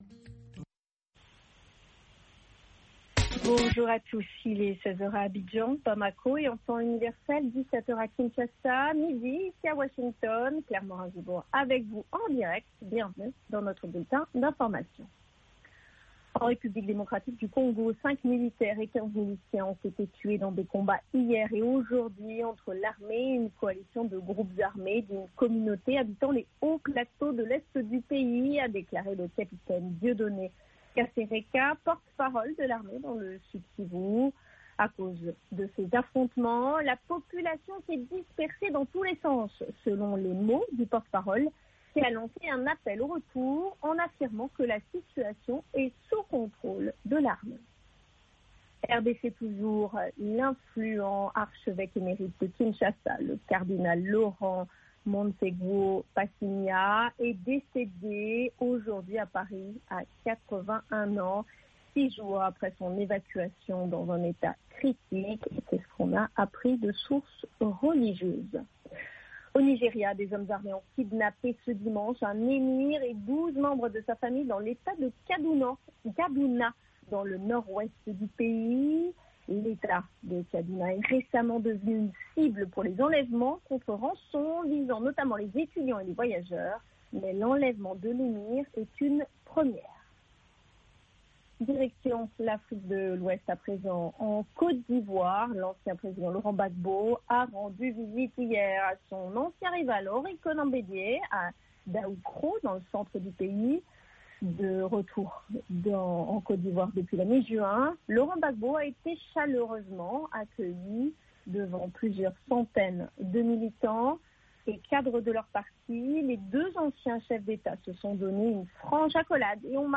5 Minute Newscast